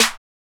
JJSnares (43).wav